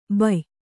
♪ bay